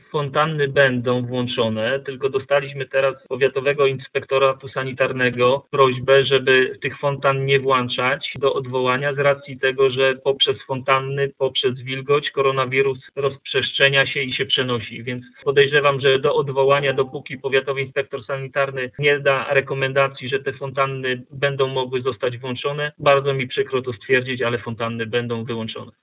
Mieleckie fontanny muszą jeszcze poczekać na rozpoczęcie swojego sezonu, tłumaczy prezydent Mielca Jacek Wiśniewski. Takie zalecenie o ich nieuruchamianiu otrzymaliśmy od Powiatowej Stacji Sanitarno -Epidemiologicznej w Mielcu, dodaje włodarz miasta.